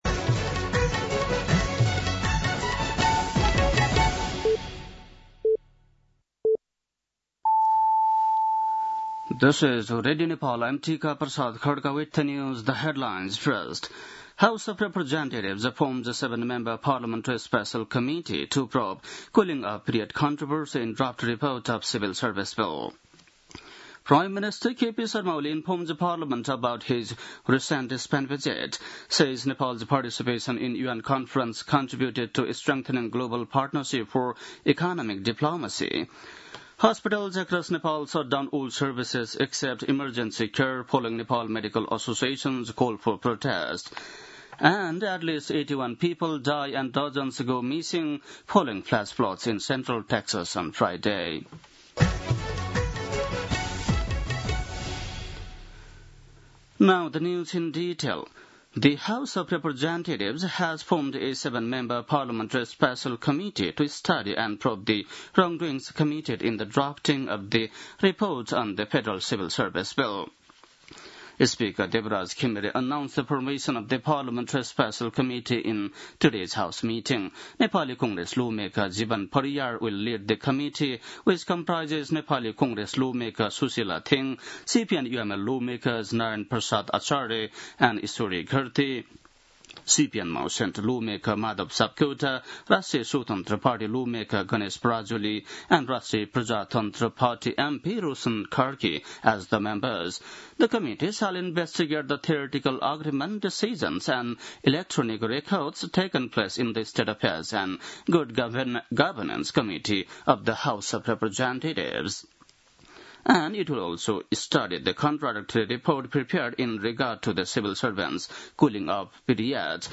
बेलुकी ८ बजेको अङ्ग्रेजी समाचार : २३ असार , २०८२
8-pm-english-news-3-23.mp3